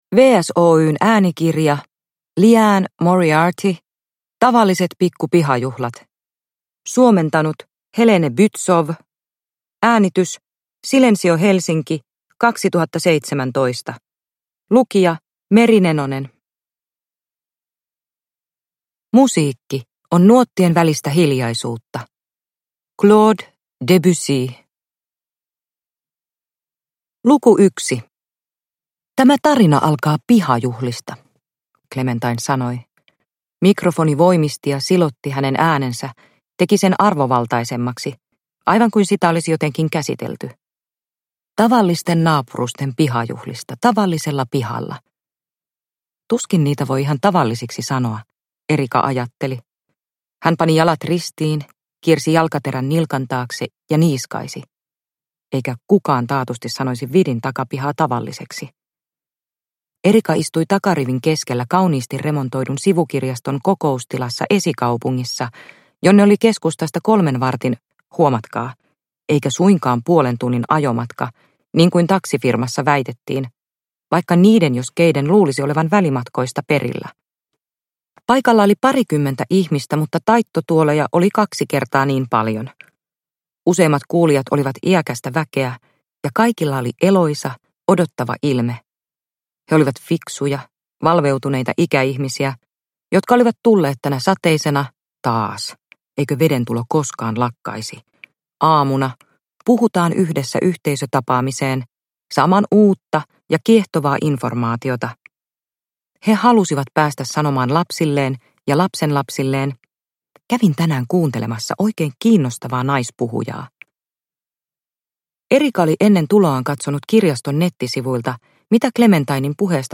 Tavalliset pikku pihajuhlat – Ljudbok – Laddas ner